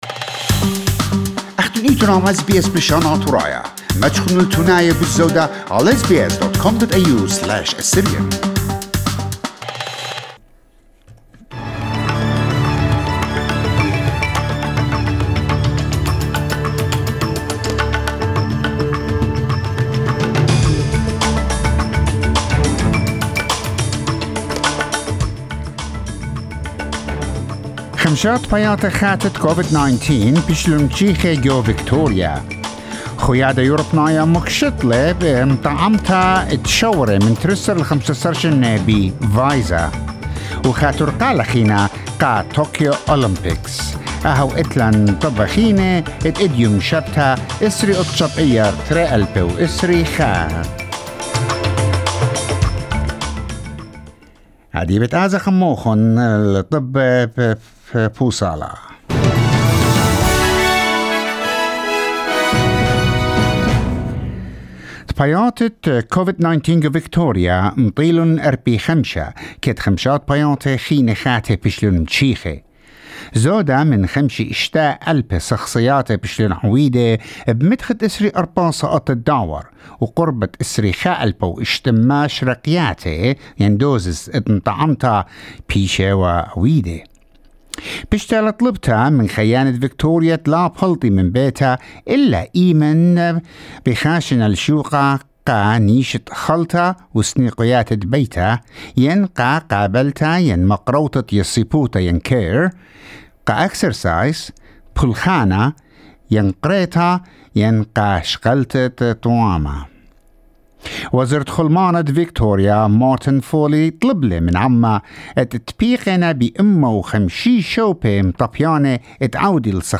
SBS News Bulletin in Assyrian 29 May 2021